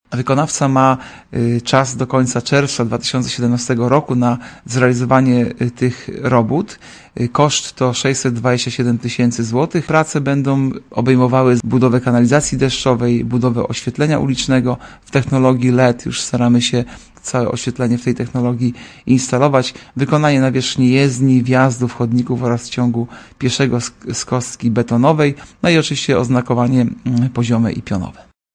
Obecnie są to ulice o nawierzchni gruntowej, bez oświetlenia, chodników i kanalizacji deszczowej. – Prace rozpoczną się tak szybko, jak pozwolą na to warunki atmosferyczne – mówi Artur Urbański, zastępca prezydenta Ełku.